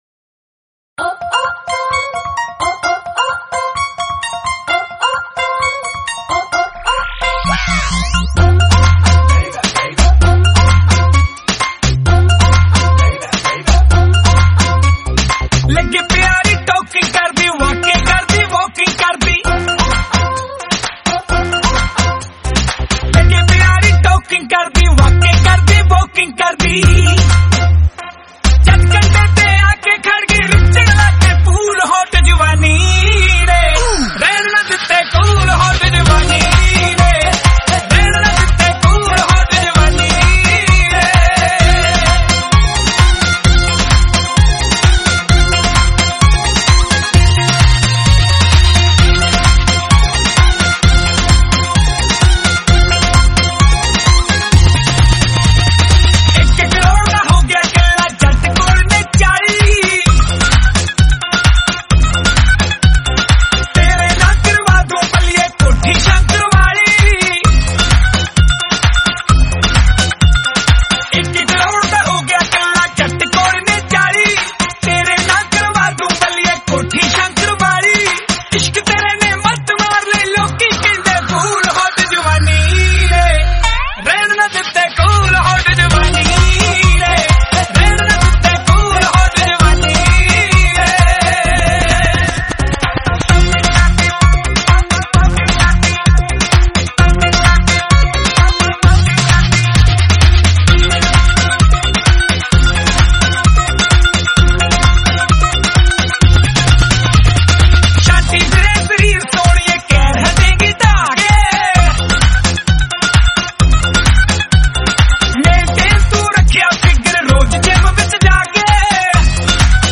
hindi song
The beautiful song in sweet voice